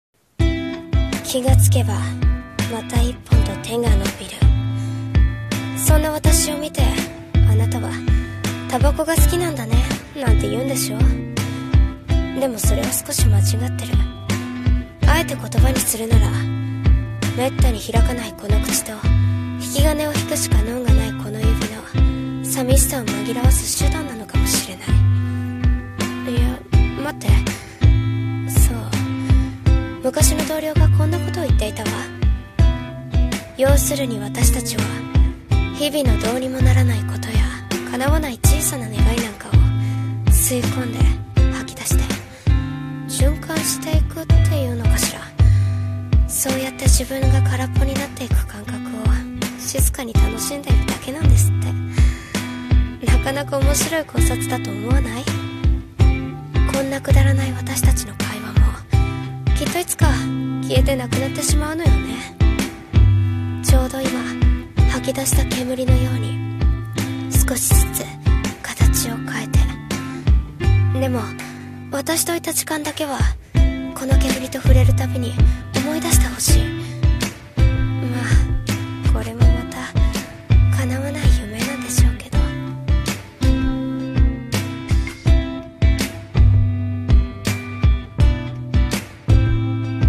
smokers【一人声劇】